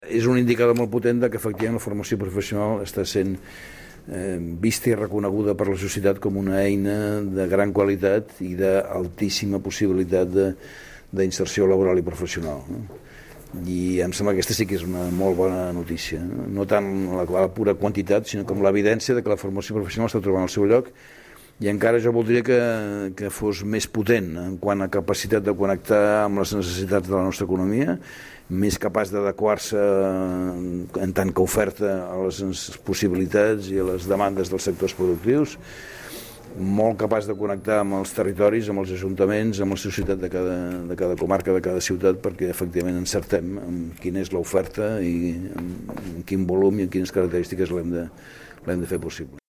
Tall de veu conseller d'Educació, Ernest Maragall